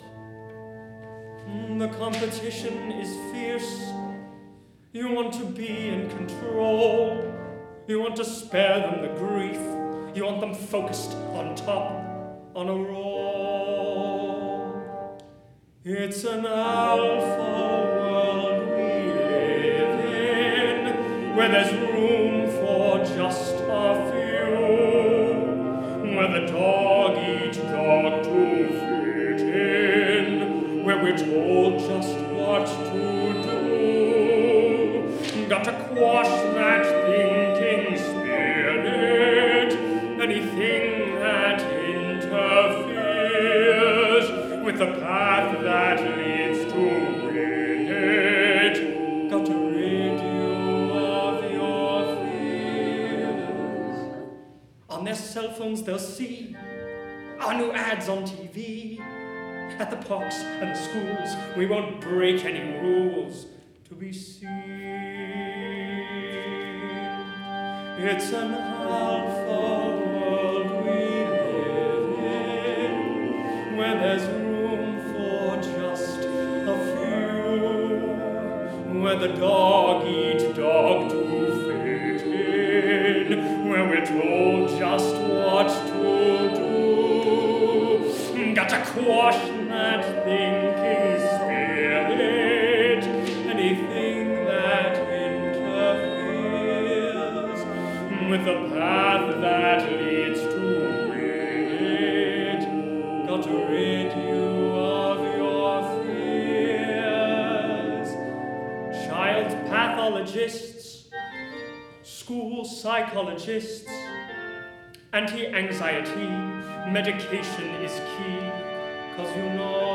A musical satire for 5 actor/singers and piano
piano